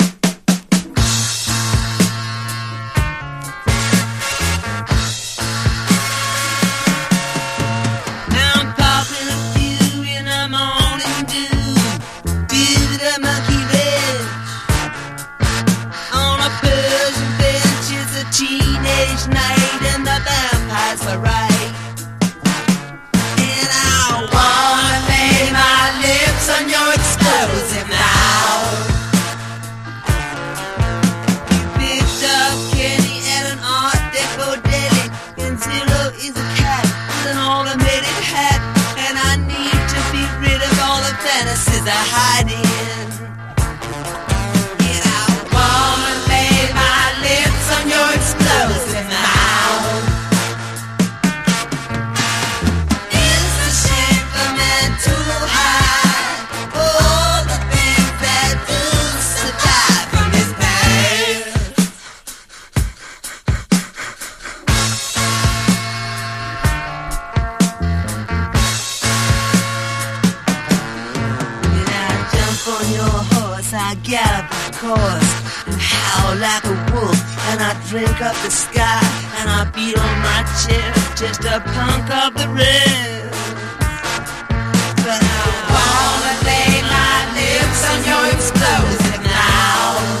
モタッたドラムが心地いい